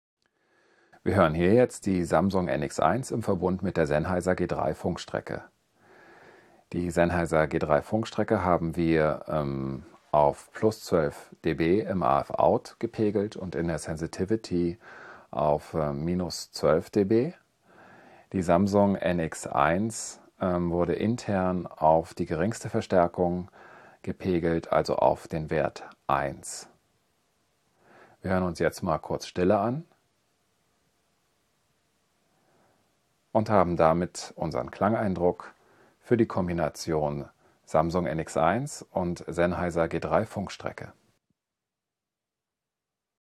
Wir haben bei den folgenden Tonbeispielen den internen Audio-Pegel der Samsung NX1 auf 1 gelassen und die jeweiligen externen Verstärker die Hauptverstärkerarbeit verrichten lassen.
Hier die normalisierte Version:
Samsung NX1 mit Sennheiser G3 Funkstrecke und Rode Lavalier
SamsungNX1_SennheiserG3_norm.wav